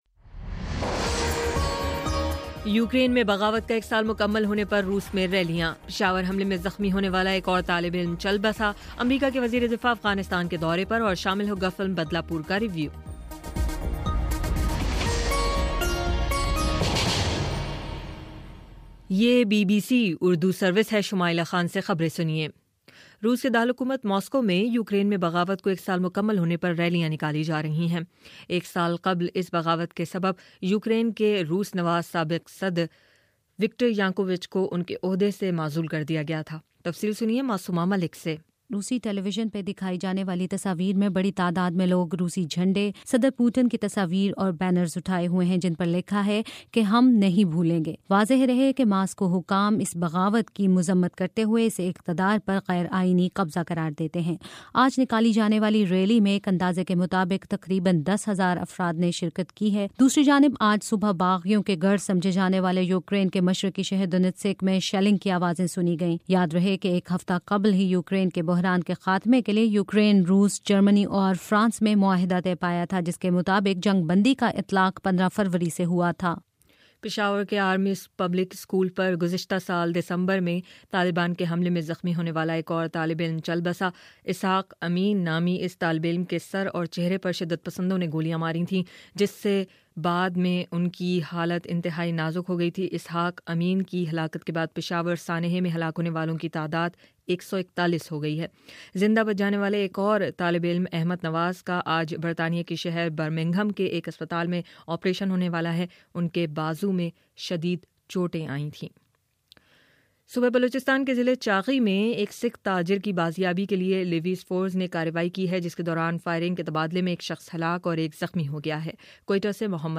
فروری21: شام سات بجے کا نیوز بُلیٹن